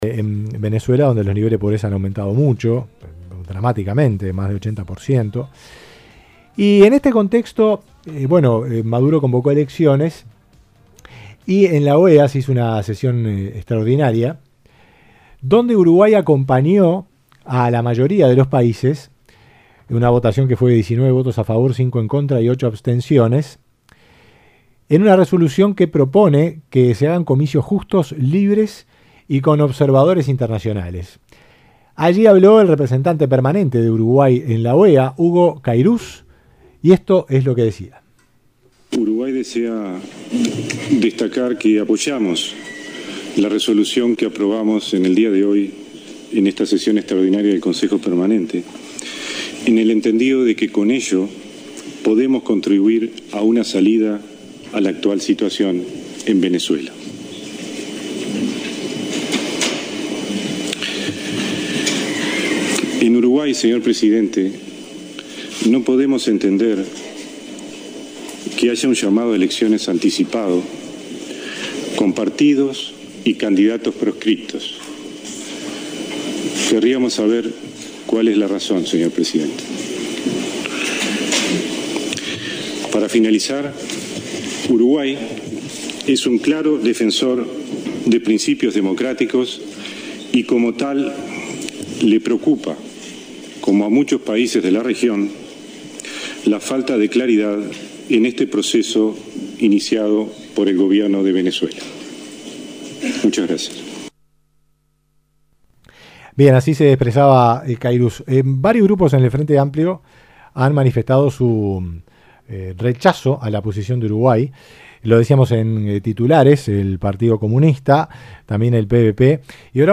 Compartimos entrevista realizada a la senadora Constanza Moreira en Radio Carve el 26 de febrero sobre la postura de Casa Grande en relación a la intervención de la OEA en Venezuela.